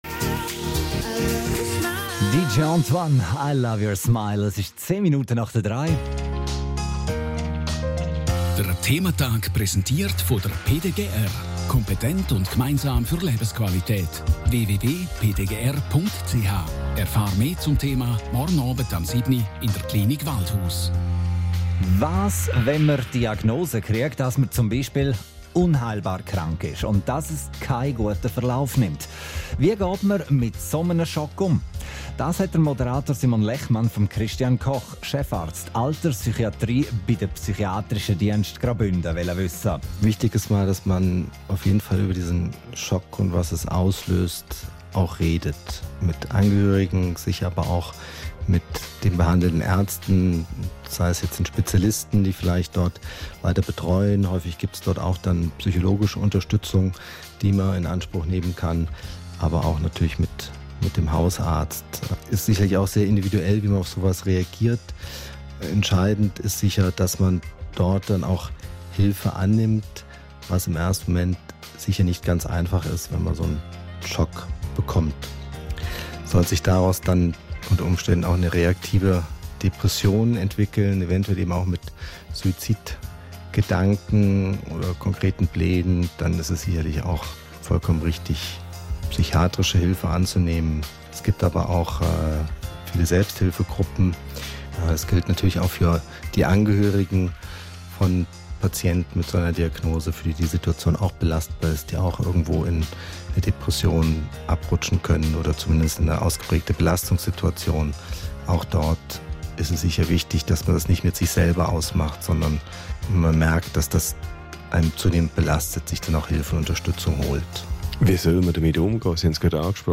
Radiobeiträge